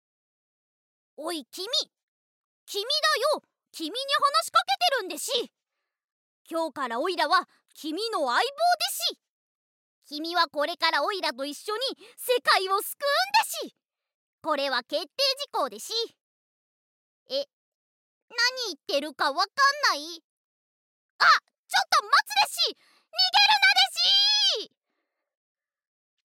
ボイスサンプル
マスコットキャラクター